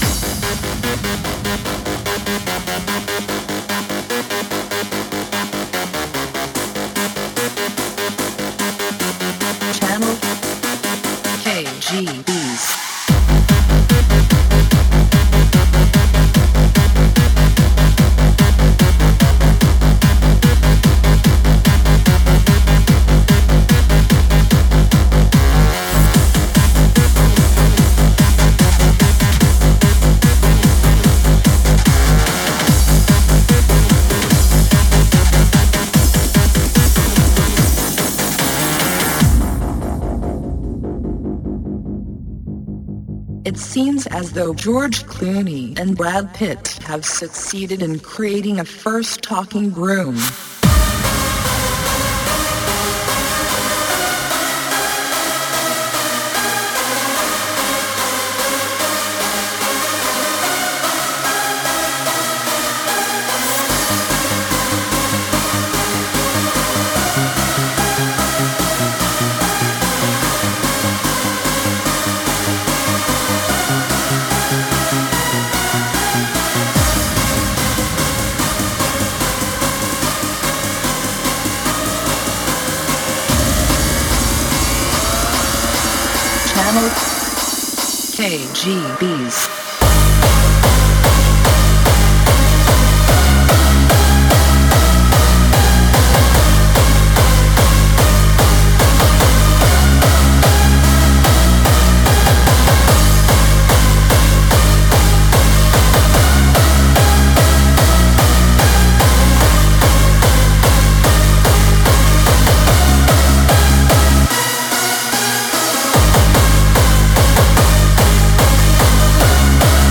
Категория: Shuffle